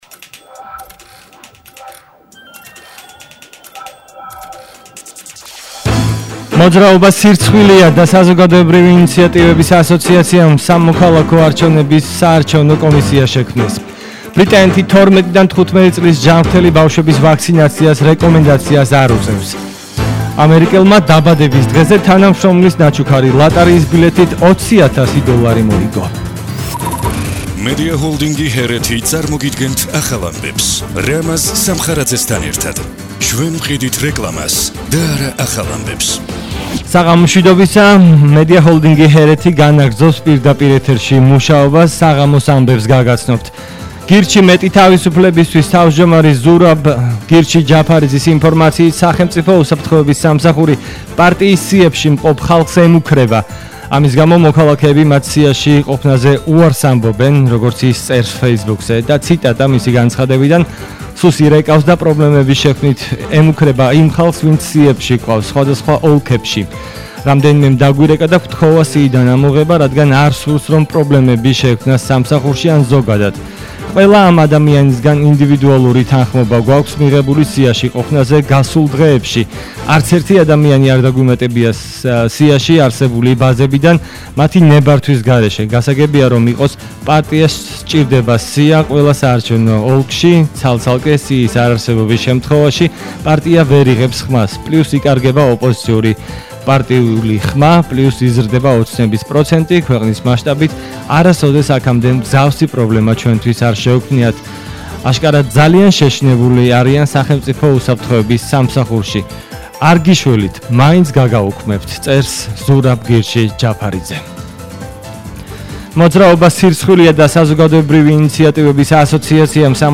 ახალი ამბები 20:00 საათზე –03/09/21